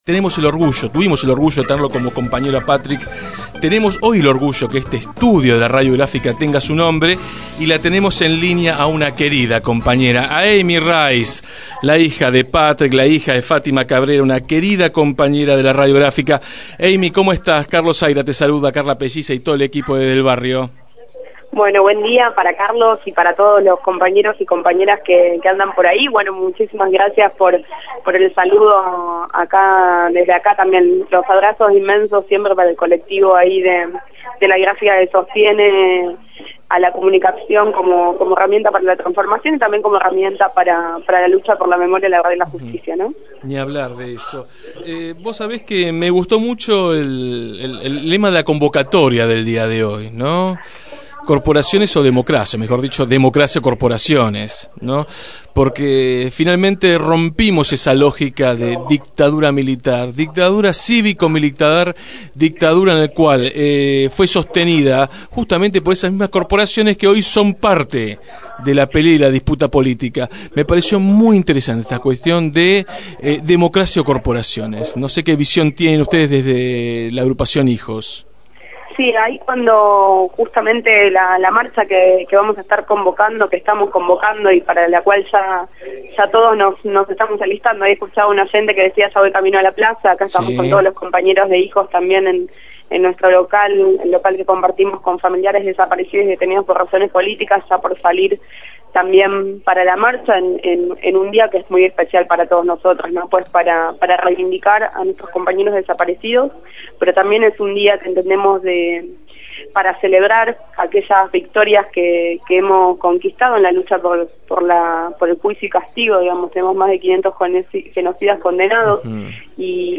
militante de la Agrupación HIJOS, fue entrevistada en Desde el Barrio.